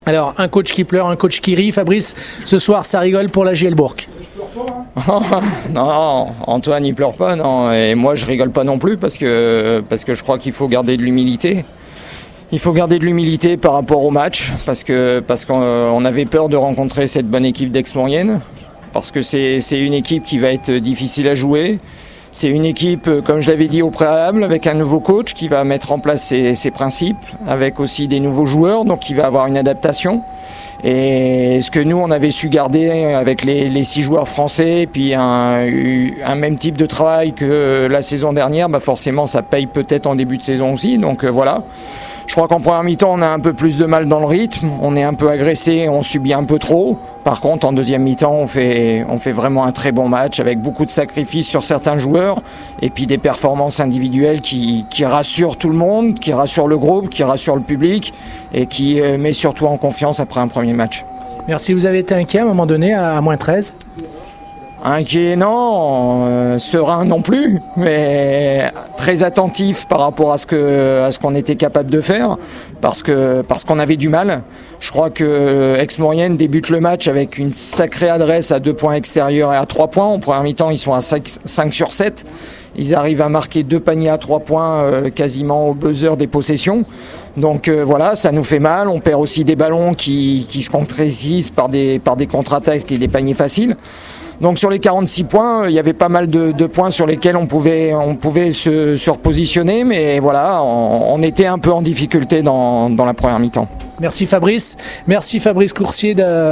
Les Bréssans ont fait preuve de patience et de combativité pour venir à bout des Savoyards, on écoute les diverses réactions d’après-match au micro